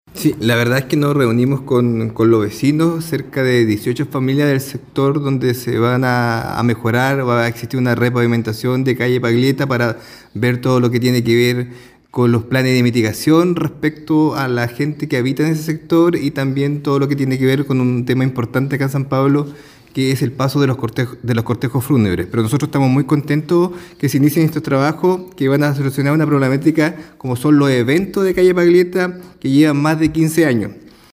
Así lo mencionó el alcalde Marco Carrillo: